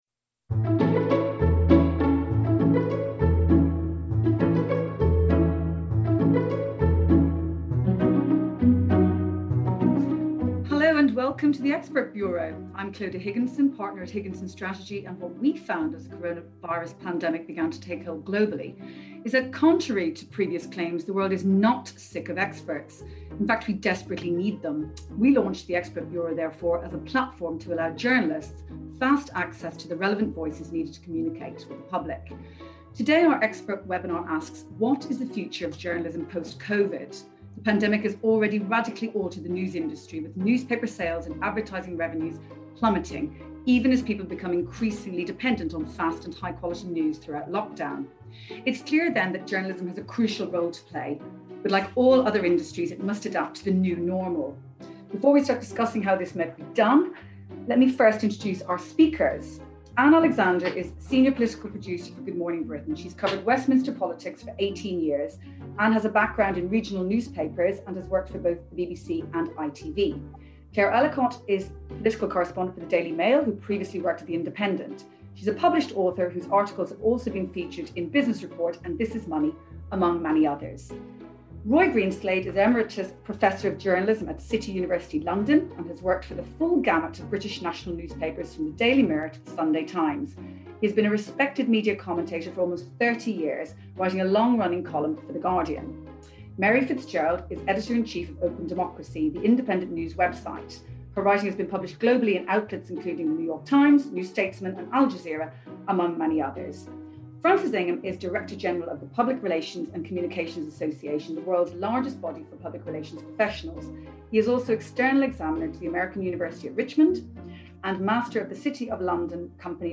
Together the panel discuss the future of journalism. They tackle subjects including how journalism should be funded, whether trust in the media has eroded, whether newspaperes can survive, and much more.